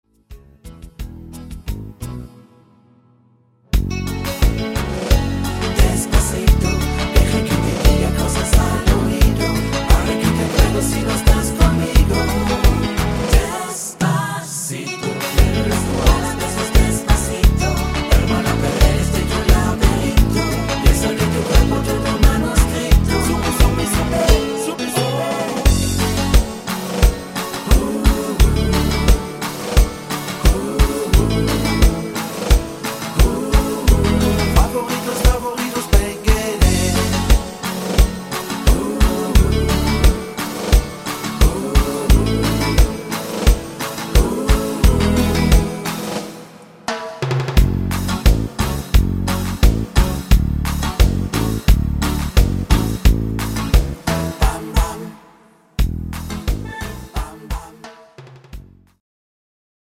tiefere Tonart